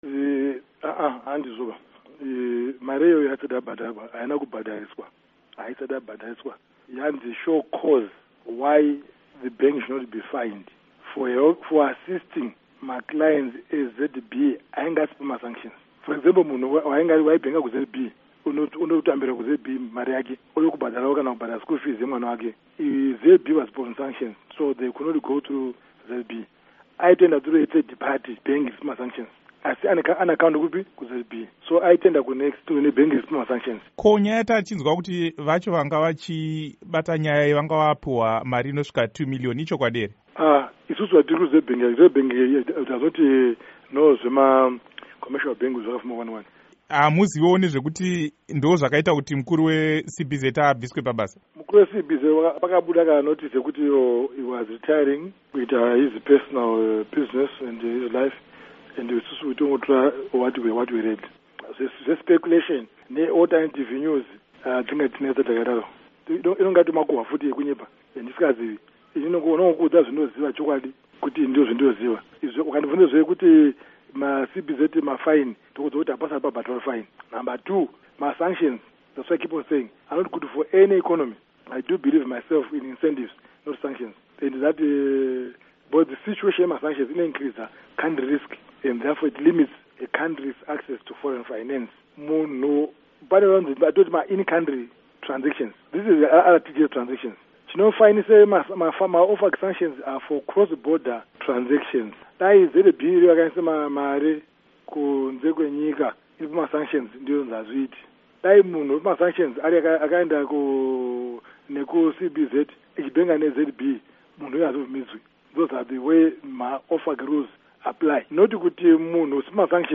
Hurukuro naDoctor John Mangudya